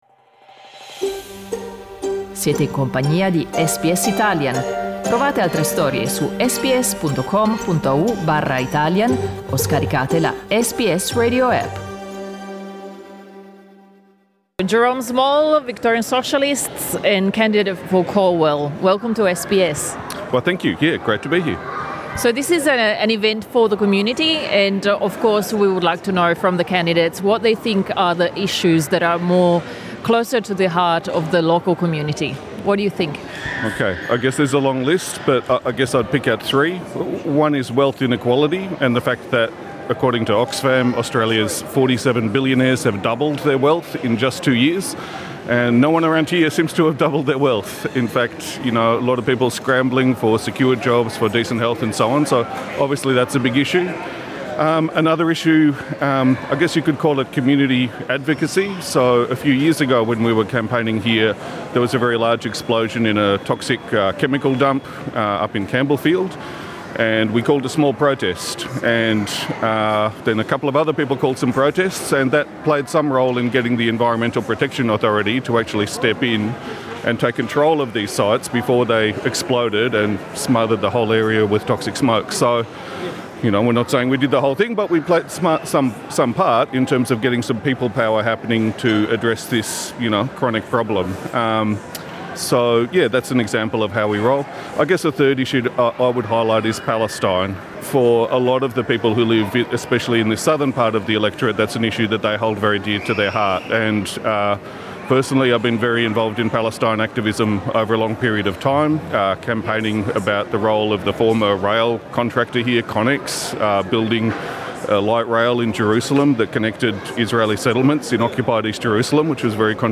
"Election Exchange" è un ciclo di incontri organizzato da SBS radio in varie città australiane, per incontrare candidate/i, esponenti delle organizzazioni comunitarie ed elettori/elettrici. Sabato 30 aprile SBS Radio ha tenuto uno degli incontri a Melbourne.
L'incontro Election Exchange con candidate/i, esponenti delle comunità etniche e comuni elettori ed elettrici di sabato 30 aprile si è tenuto a Broadmeadows, quartiere nella zona nord di Melbourne.